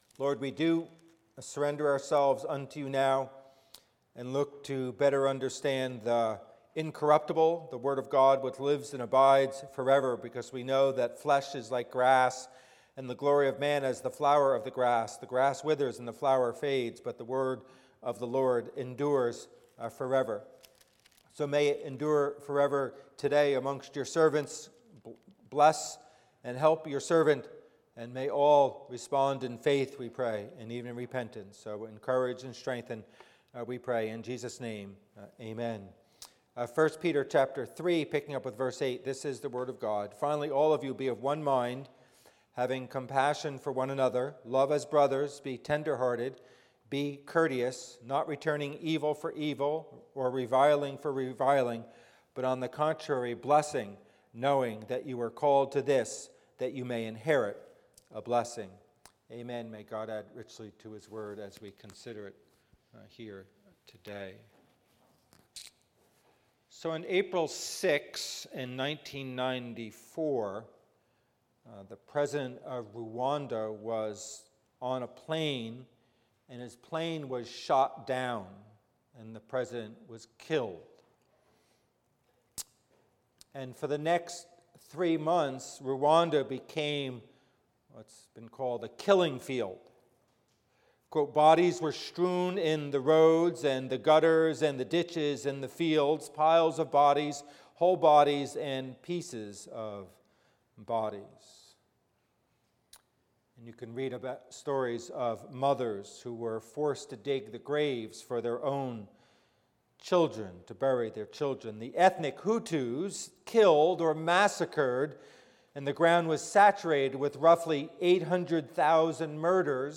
Sermon: Only with a New Heart Can One Act This Way Toward Brothers and Enemies
Passage: 1 Peter 3:8-9 Service Type: Worship Service